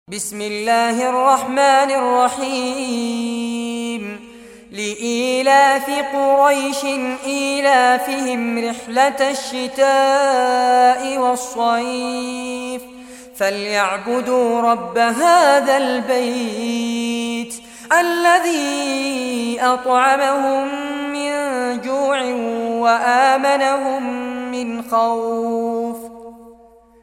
Surah Quraysh Recitation by Fares Abbad
Surah Quraysh, listen or play online mp3 tilawat / recitation in Arabic in the beautiful voice of Sheikh Fares Abbad.